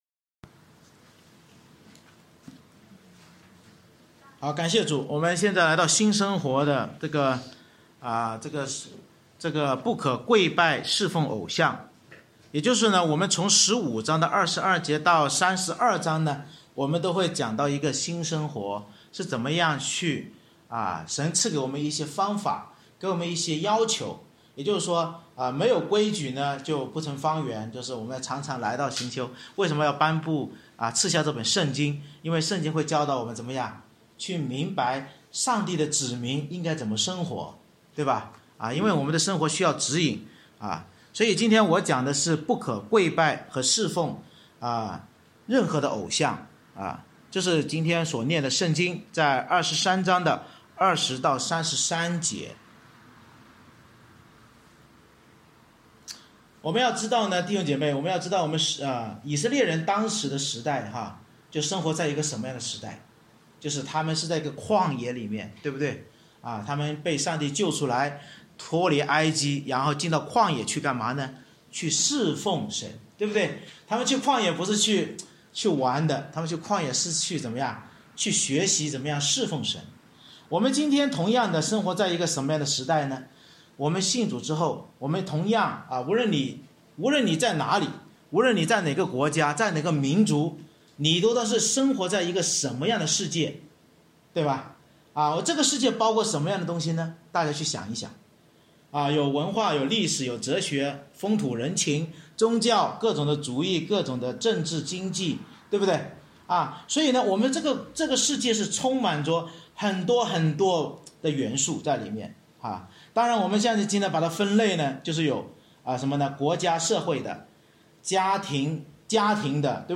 《出埃及记》讲道系列
出埃及记23：20-33 Service Type: 主日崇拜 Bible Text